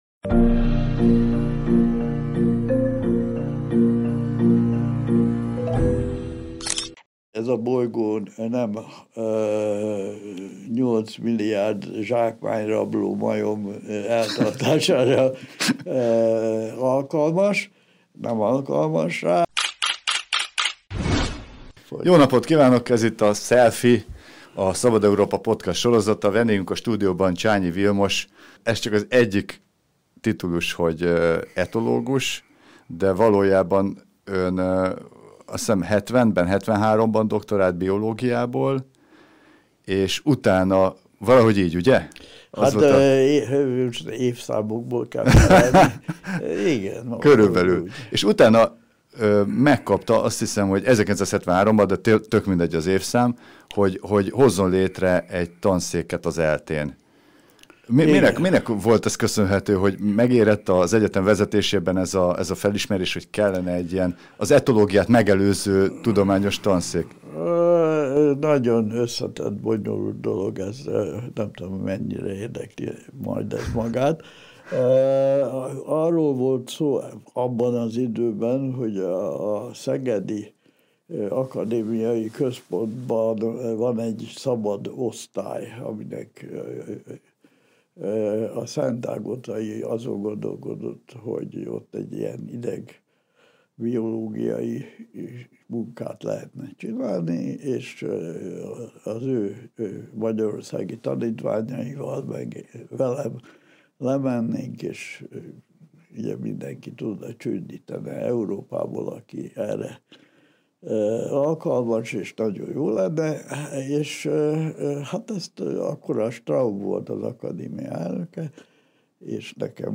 A harmadik világháború után valószínűleg visszatérünk az igazságosabb, ősközösség előtti elosztáshoz, ez a bolygó nem alkalmas nyolcmilliárd zsákmányrabló majom eltartására – véli Csányi Vilmos biológus, etológus. Vele beszélgettünk zsákmányrablásról, afrikai vadkutyákról és az emberiség jövőjéről.